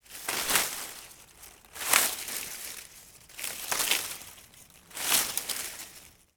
bushes.wav